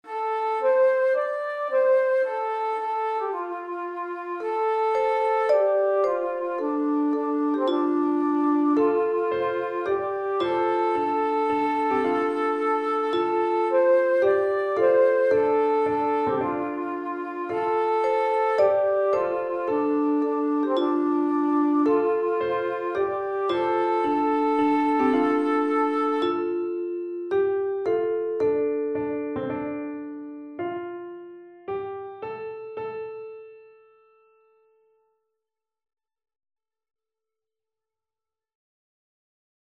Driestemmig